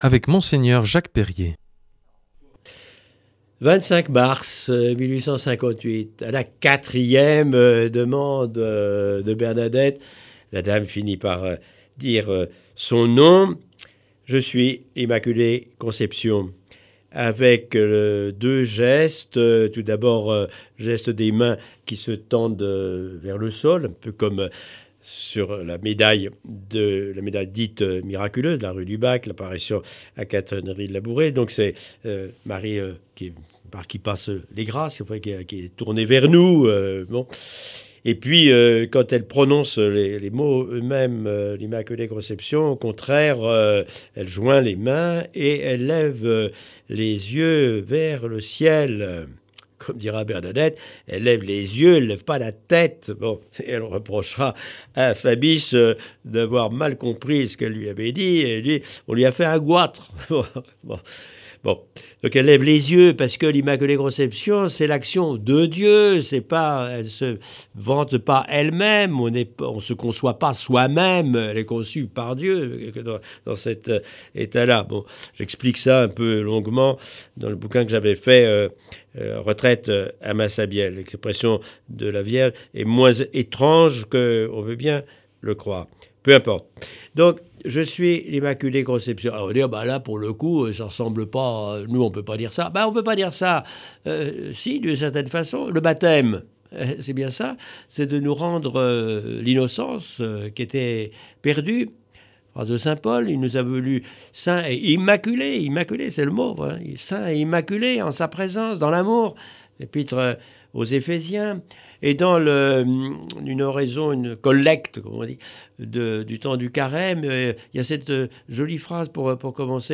L’enseignement marial de cette semaine nous est proposé par Mgr Jacques Perrier. Il nous amène à faire un parallèle entre l’itinéraire de Bernadette Soubirous à travers les apparitions à Lourdes et la vie de la Vierge Marie.